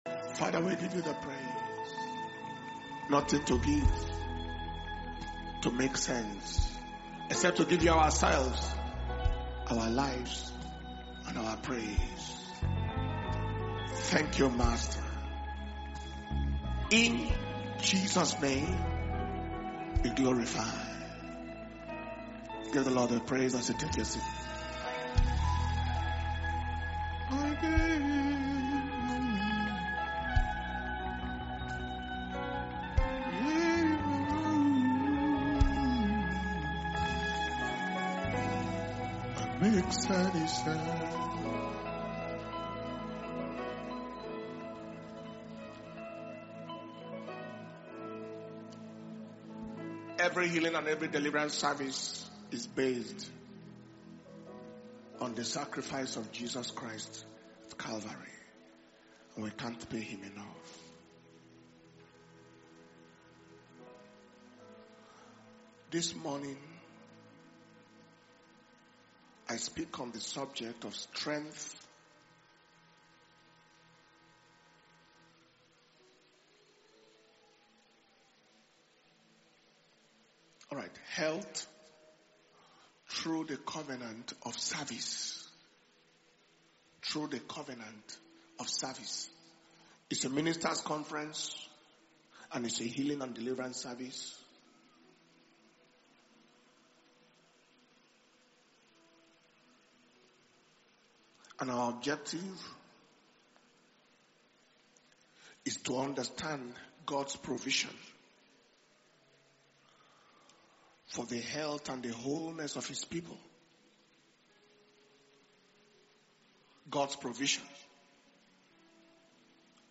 Dunamis International Ministers’ Flaming Fire Conference (IMFFC 2025)
August 2025 – Day 2 Morning